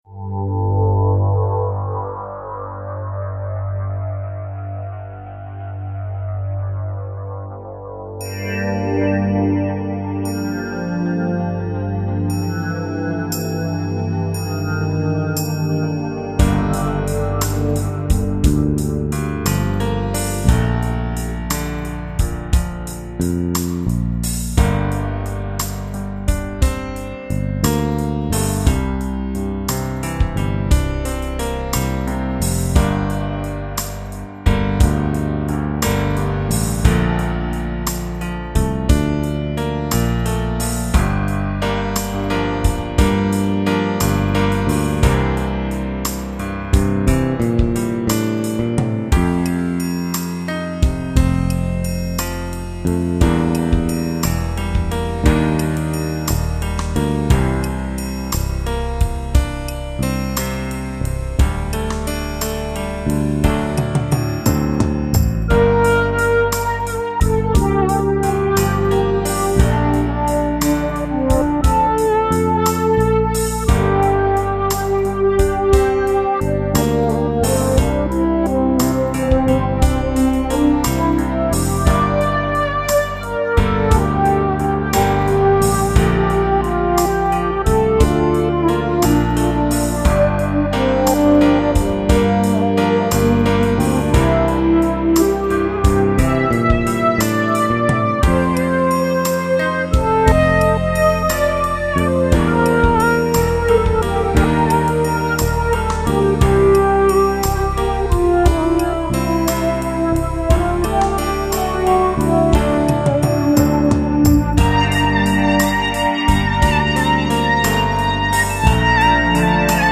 Batterie Solo